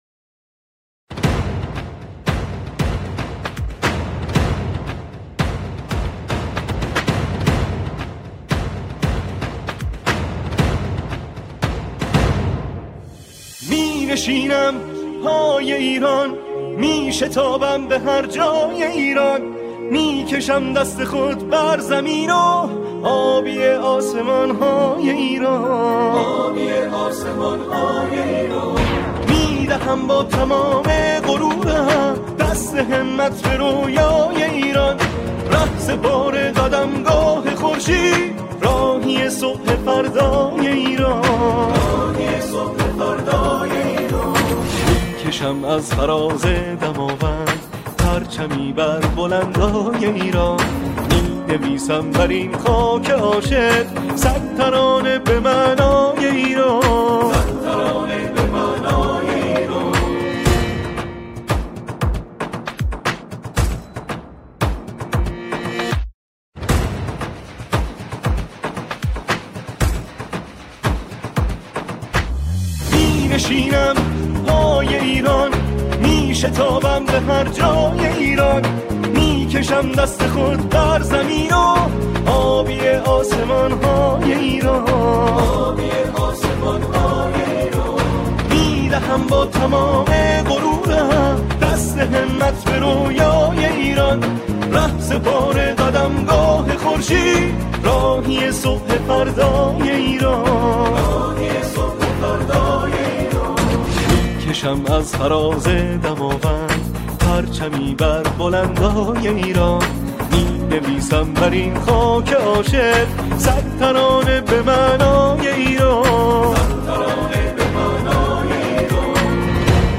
در این قطعه، او، شعری را با موضوع ایران همخوانی می‌کند.